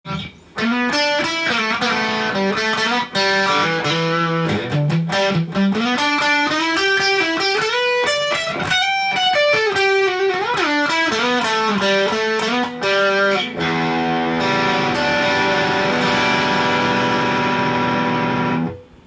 ロックなギターソロも良い感じでポジションチェンジ出来そうです♪